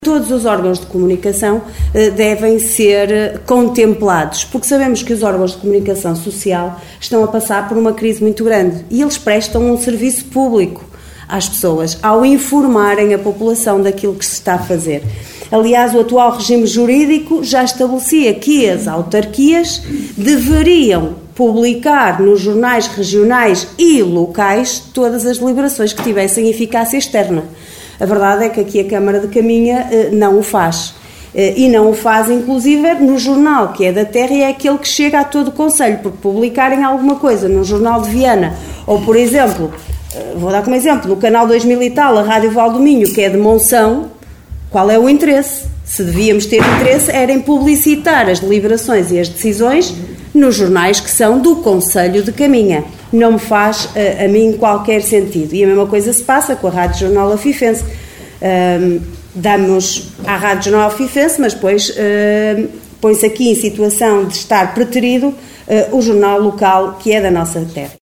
Excertos da última reunião de Câmara, realizada na passada quarta-feira no Salão Nobre dos Paços do Concelho, para contratação de 10 funcionários e atribuição de subsídios.